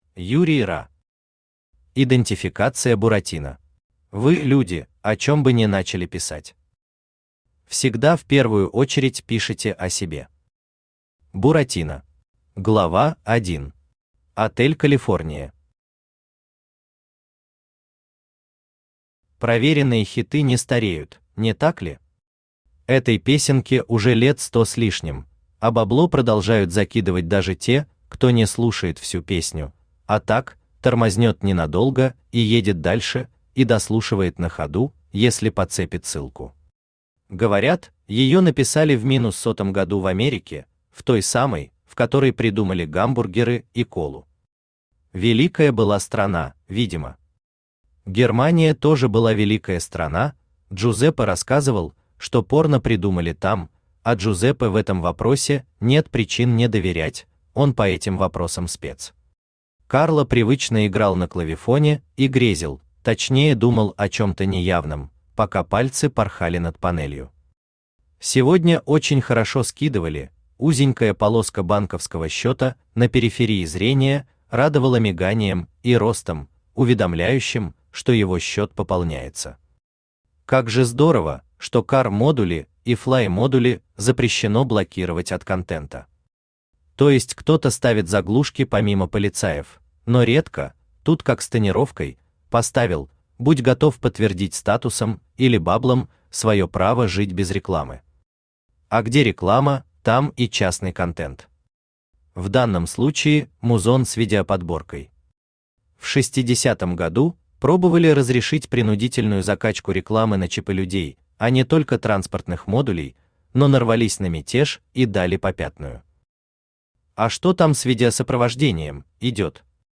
Аудиокнига Идентификация Буратино | Библиотека аудиокниг
Aудиокнига Идентификация Буратино Автор Юрий Ра Читает аудиокнигу Авточтец ЛитРес.